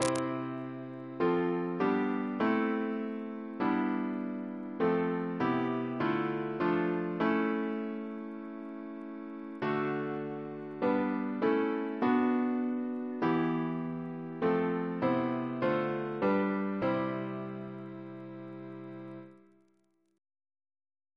Double chant in C Composer: Bruce Neswick (b.1956)